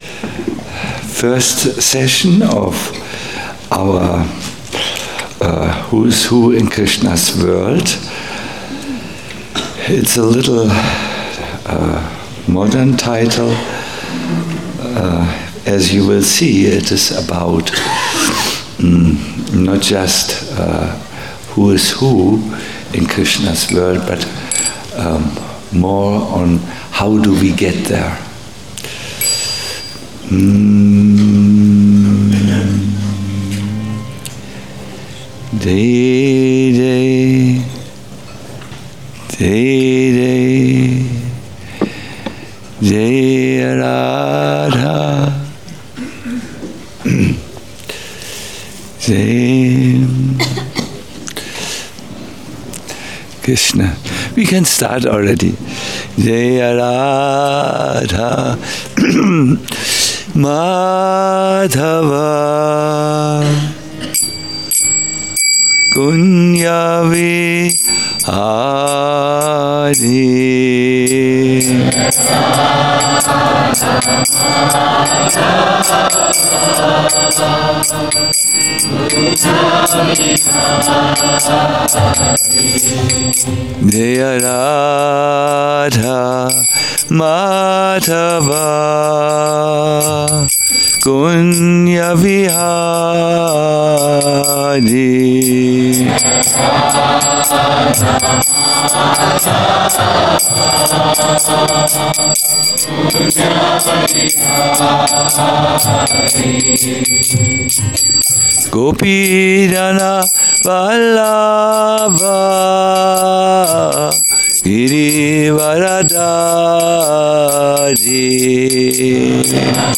Govardhana Retreat - Vraja Vilasa Stavah 1 - Who is who in Krsnas world - a lecture
Govardhana Retreat Center